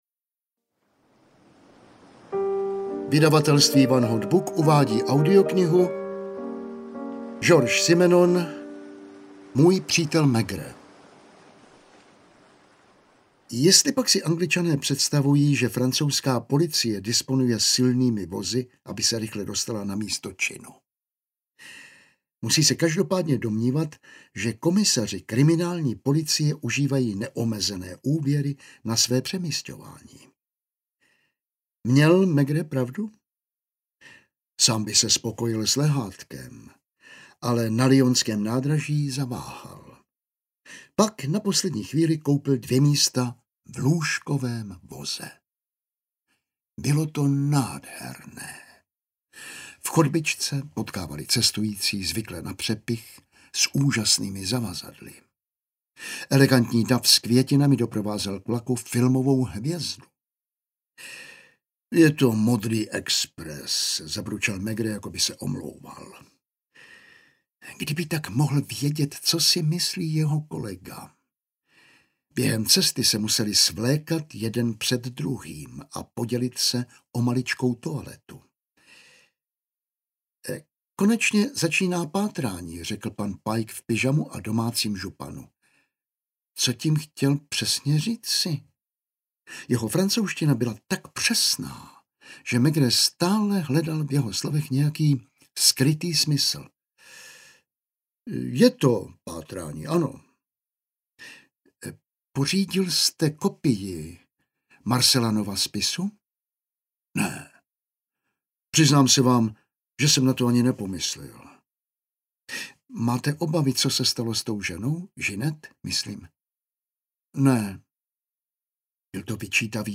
Můj přítel Maigret audiokniha
Ukázka z knihy